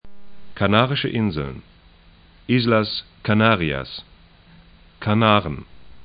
Kanaren   ka'na:rɪʃə 'ɪnzln
ka'na:rən   Islas Canarias 'i:zlas ka'na:rĭas es